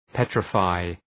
Προφορά
{‘petrə,faı}